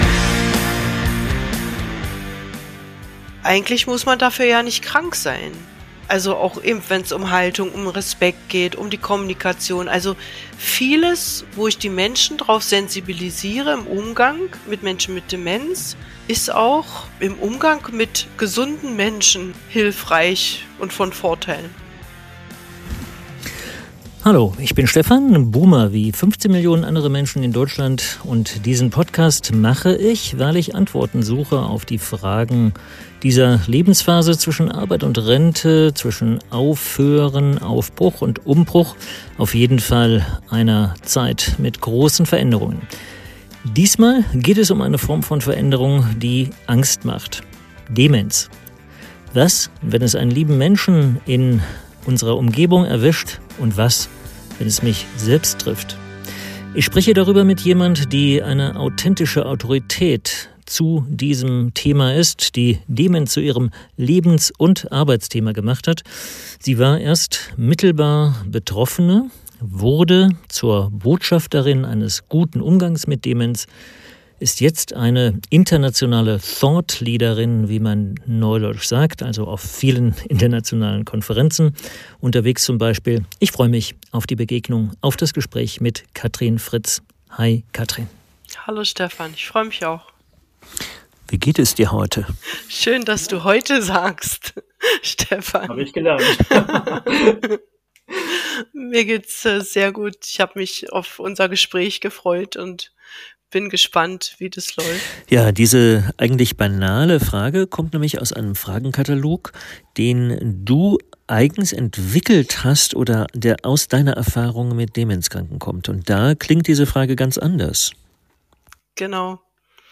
Ein Gespräch über Erinnerungen, Würde, Nähe.